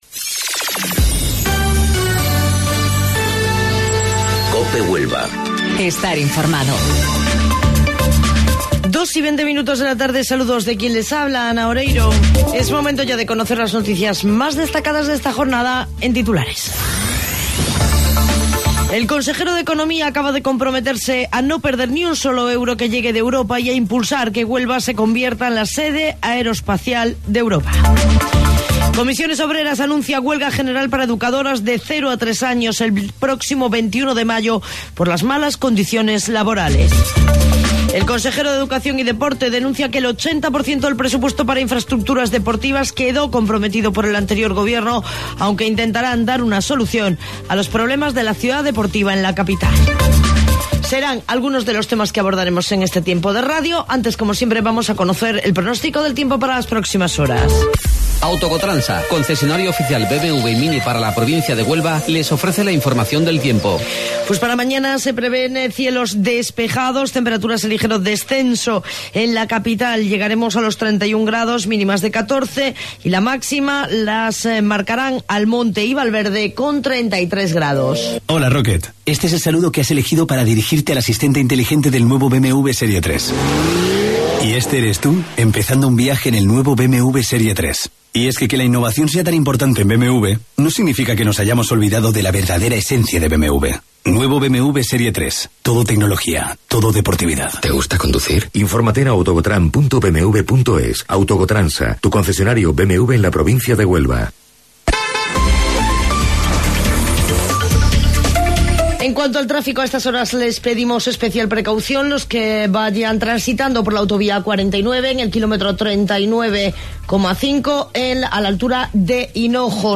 AUDIO: Informativo Local 14:20 del 13 de Mayo